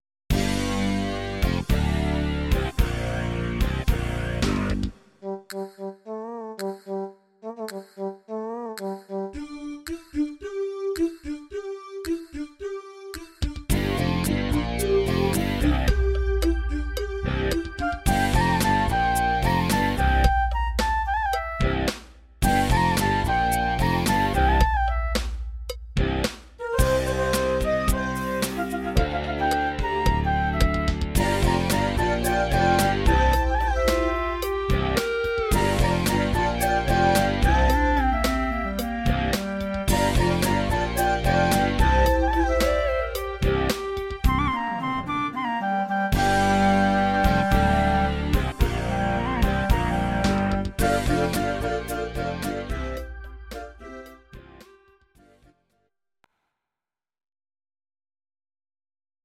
Audio Recordings based on Midi-files
Pop, Musical/Film/TV, 2000s